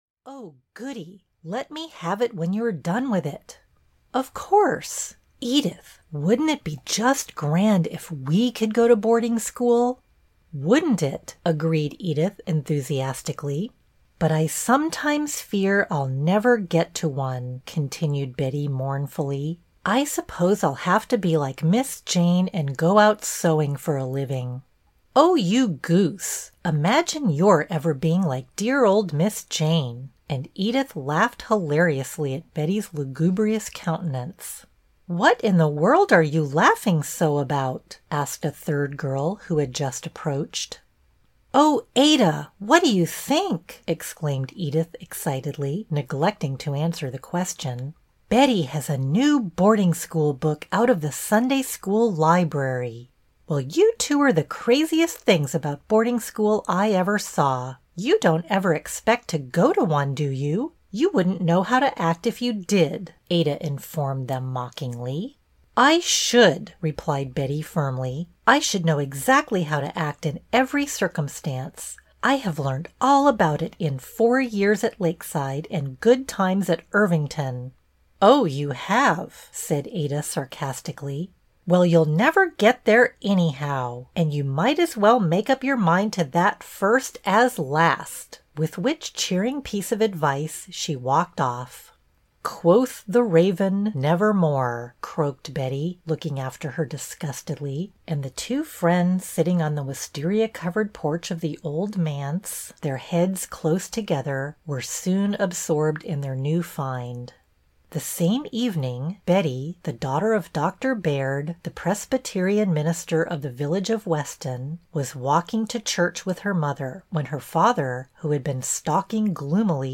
Betty Baird (EN) audiokniha
Ukázka z knihy